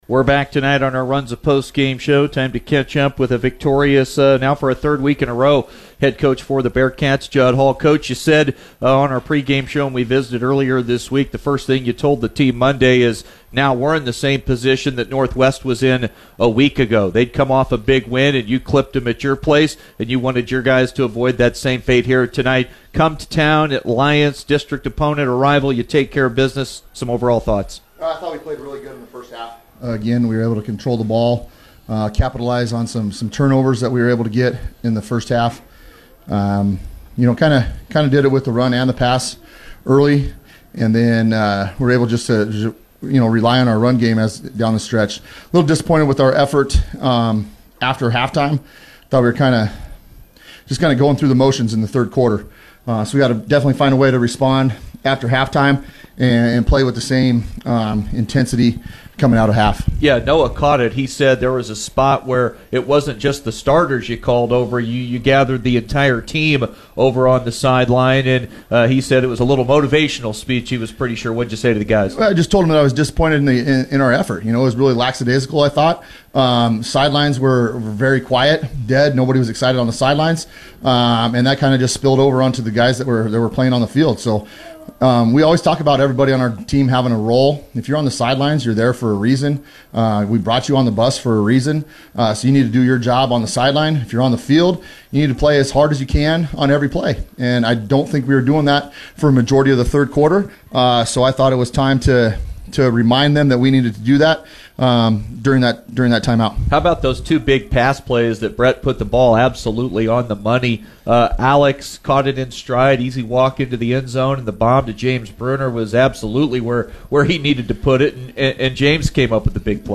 on the postgame show.